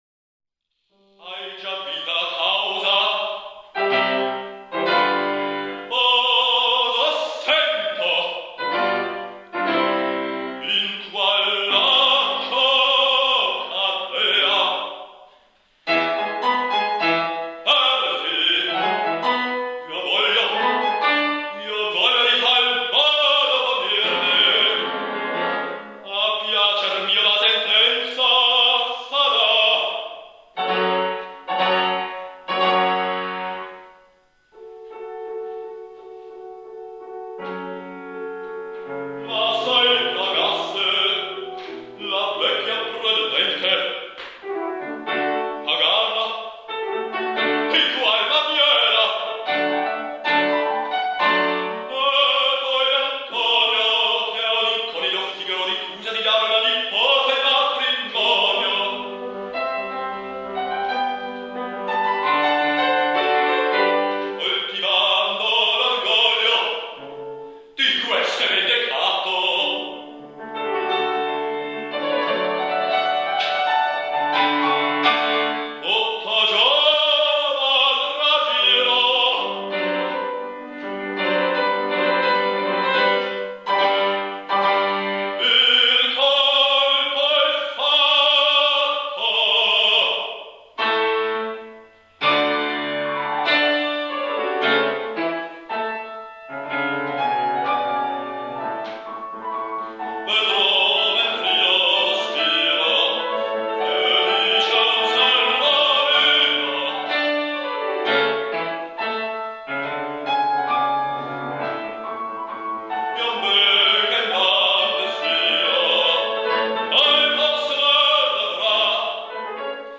Bassbariton